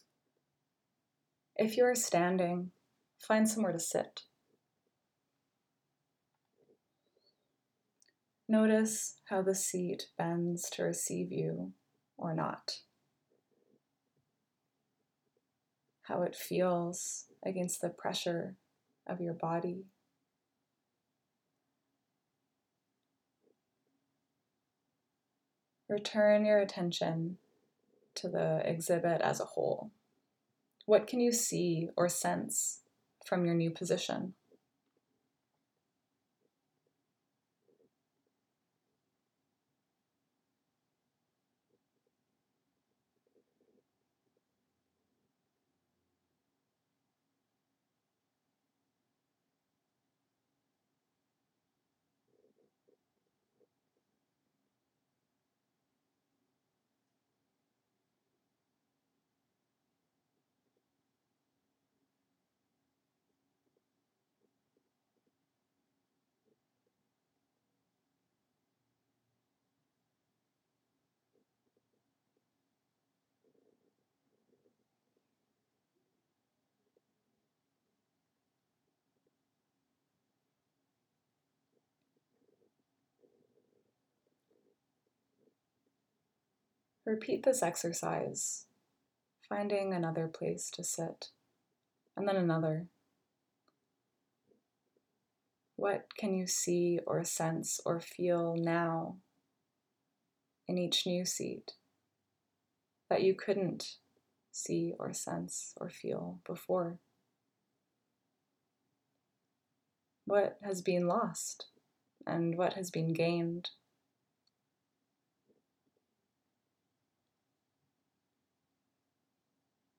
This is a short, guided sound walk through Soundings.